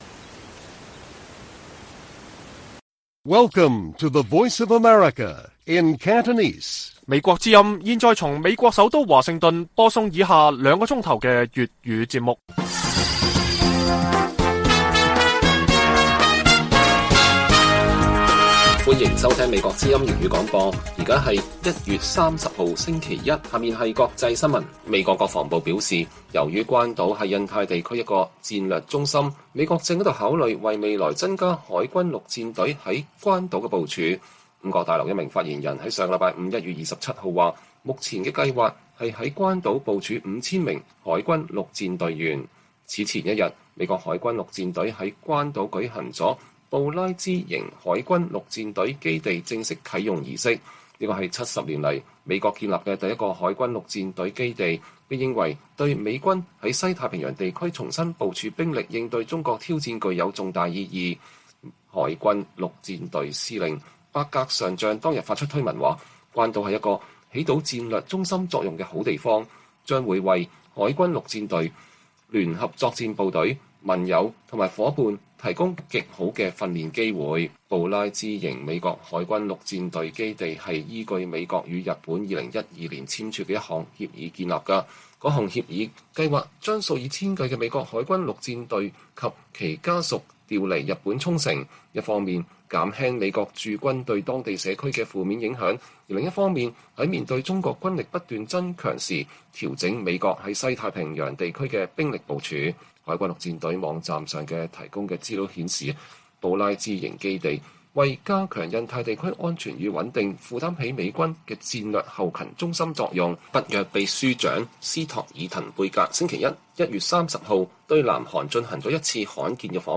粵語新聞 晚上9-10點: 美國正考慮未來在關島增強部署海軍陸戰隊